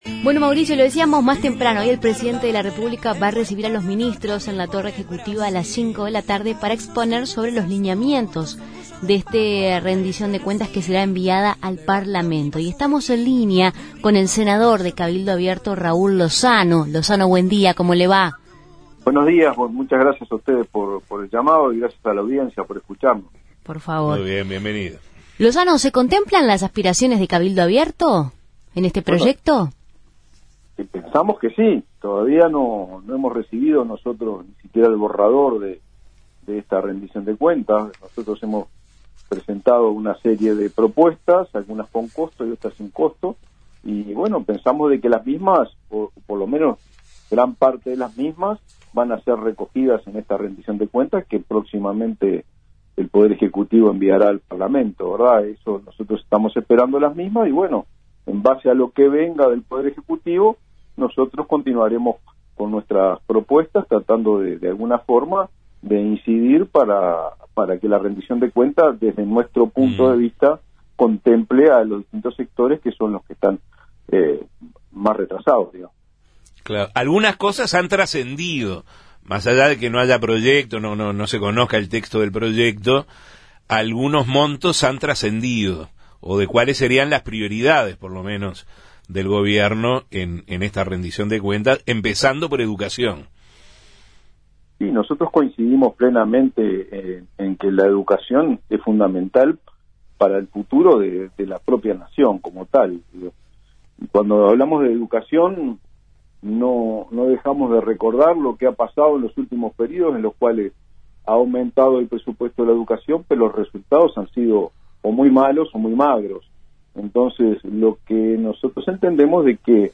En la previa, el senador de Cabildo Abierto Raúl Lozano fue consultado por Justos y pecadores sobre si las propuestas y aspiraciones de su partido serían tenidas en cuenta.